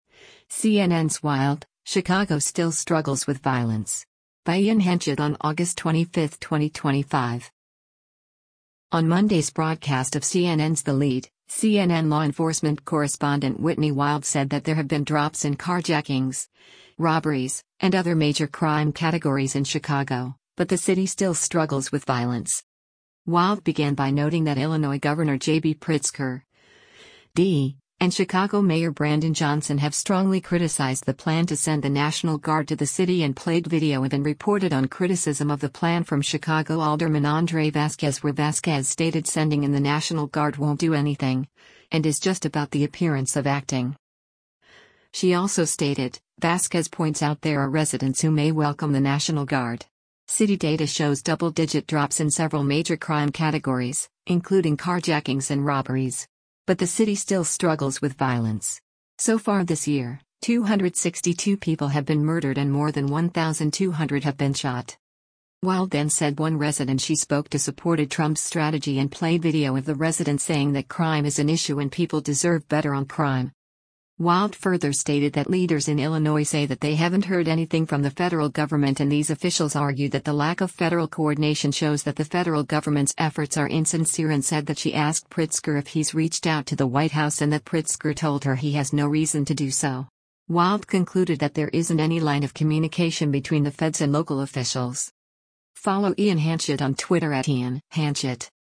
On Monday’s broadcast of CNN’s “The Lead,” CNN Law Enforcement Correspondent Whitney Wild said that there have been drops in carjackings, robberies, and other major crime categories in Chicago, “But the city still struggles with violence.”
Wild then said one resident she spoke to supported Trump’s strategy and played video of the resident saying that crime is an issue and people deserve better on crime.